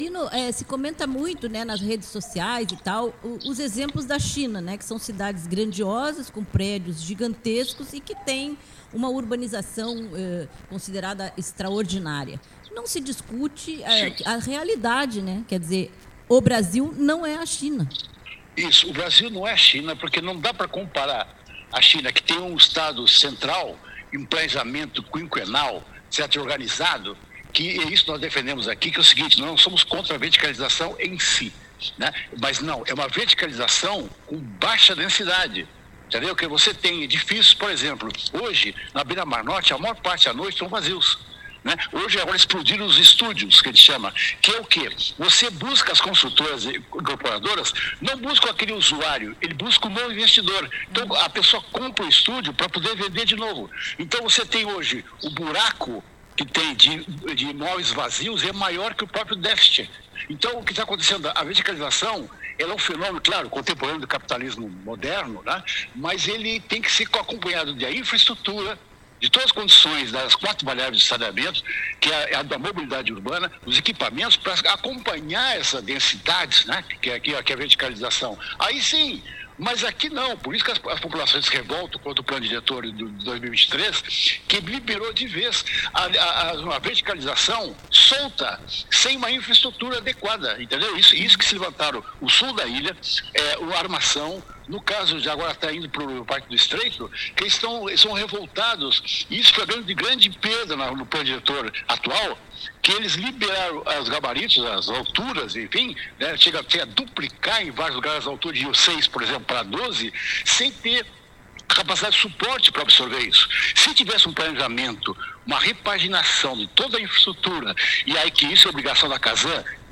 Em Florianópolis, o sul da ilha já está mobilizado contra a verticalização, mas vai ser preciso muita luta para barrar esse crescimento sem planejamento. Entrevistamos